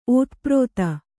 ♪ ōtaprōta